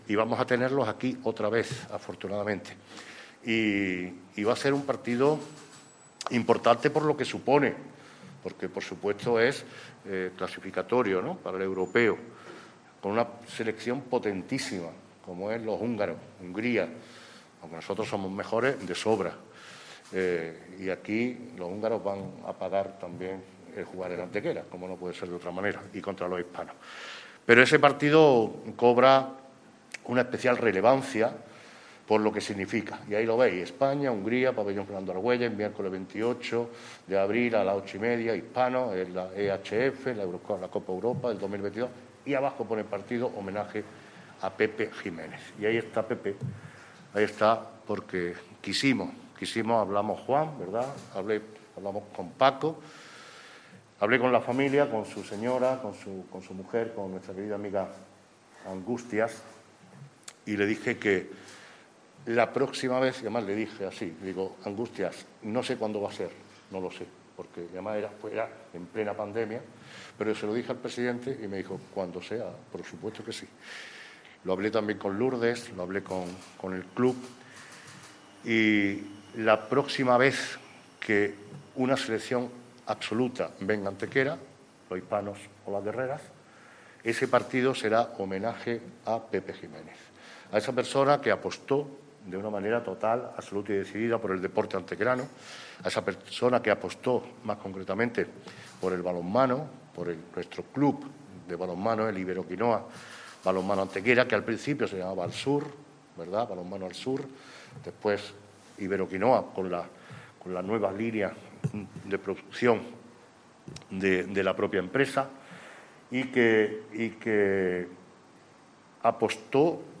ENLACE A VIÍDEO DE LA RUEDA DE PRENSA EN YOUTUBE
Cortes de voz